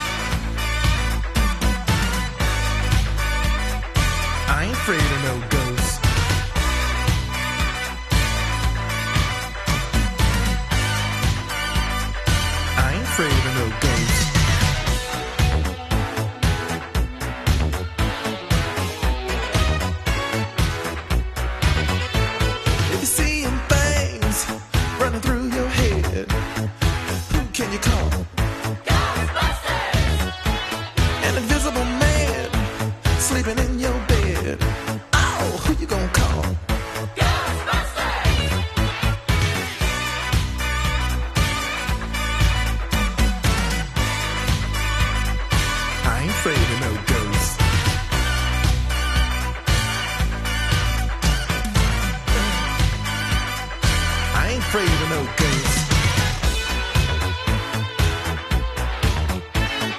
Slimer GHOSTBUSTERS Noise Making Toy sound effects free download